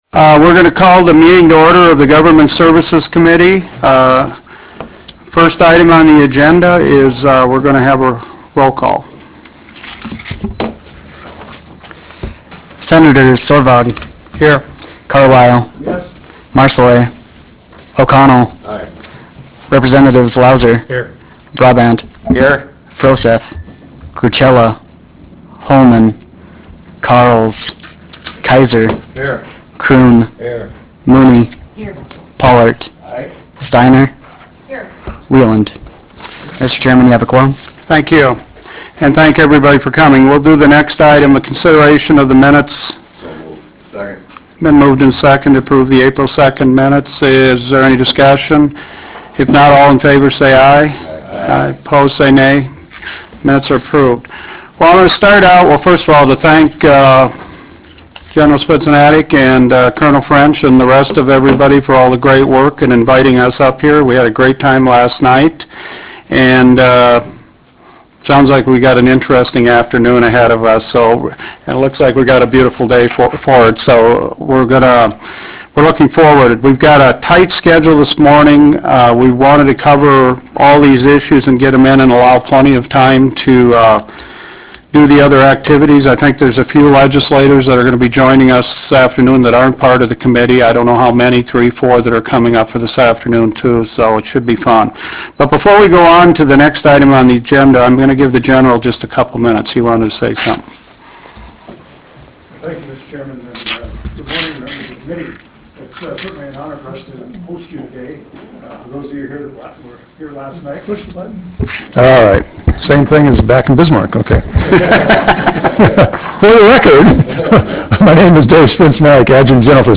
Room 231, Readiness Center Camp Grafton Devils Lake, ND United States
Meeting Audio